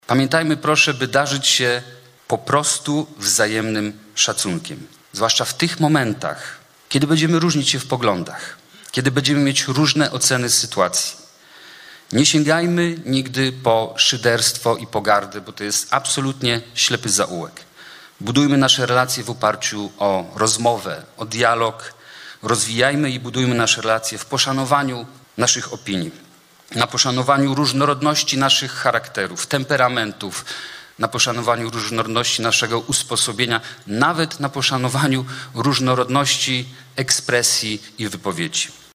Inauguracyjna sesja Rady Miejskiej w Myśliborzu